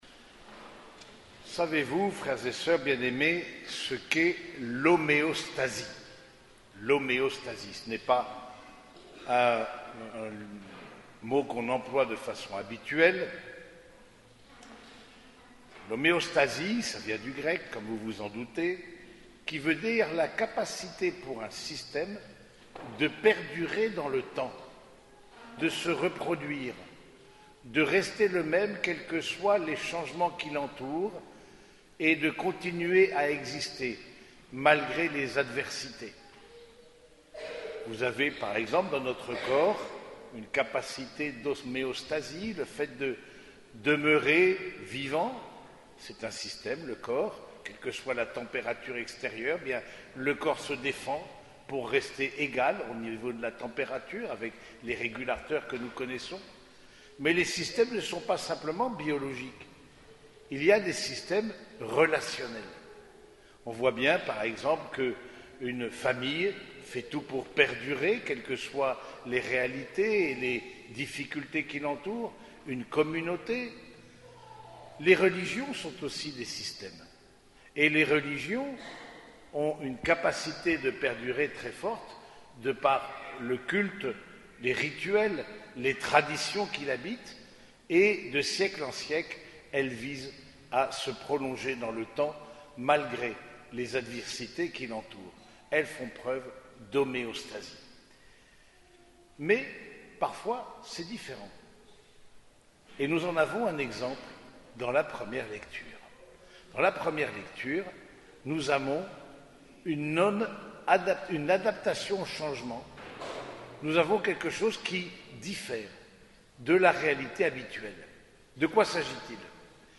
Homélie du sixième dimanche de Pâques